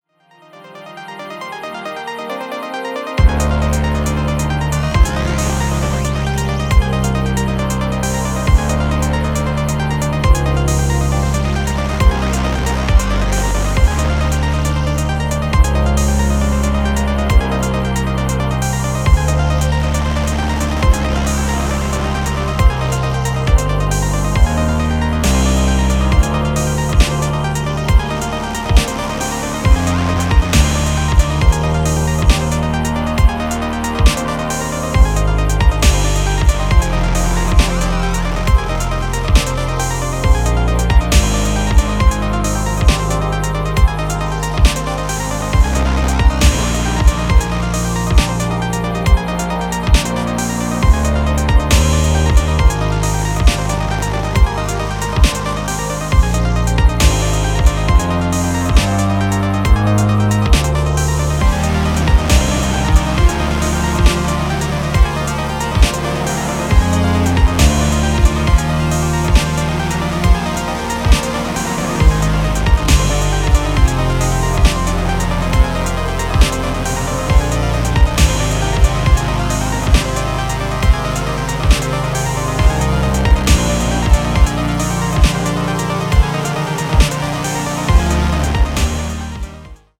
cinematic electronica